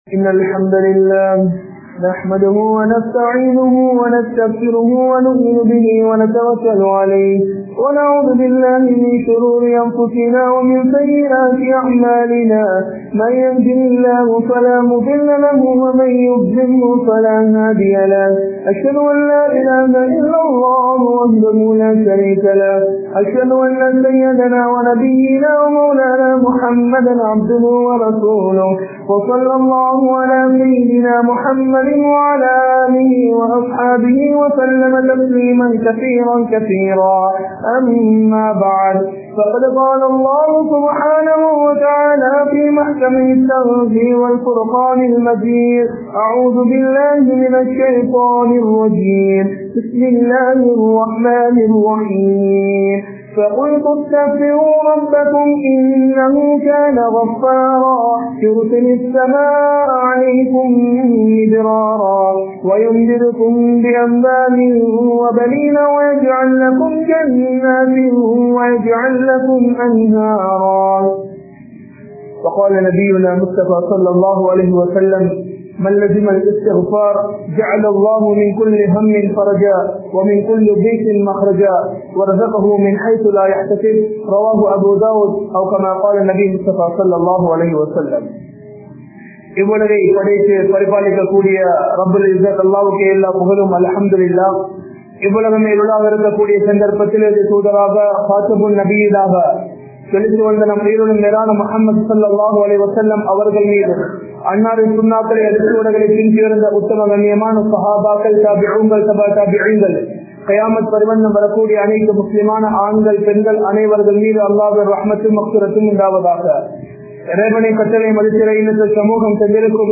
Aniyaayam Seithavarhal Thappa Mudiyaathu (அநியாயம் செய்தவர்கள் தப்ப முடியாது) | Audio Bayans | All Ceylon Muslim Youth Community | Addalaichenai